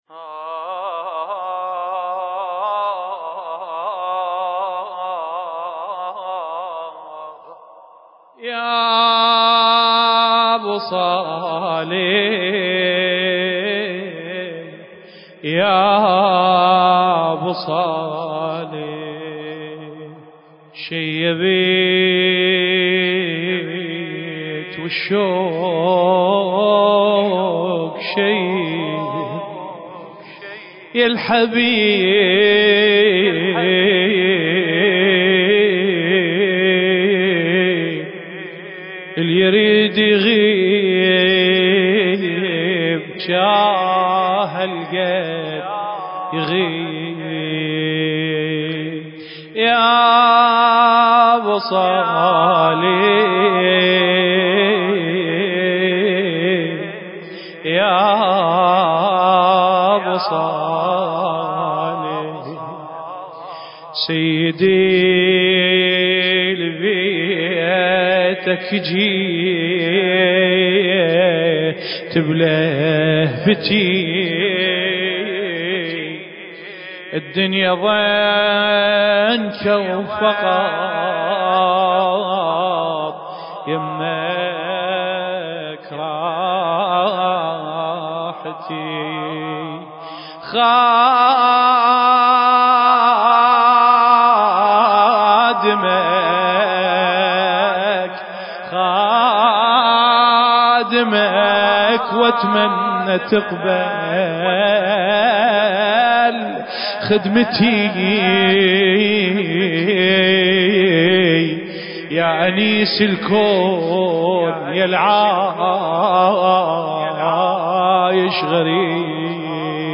المكان: العتبة العسكرية المقدسة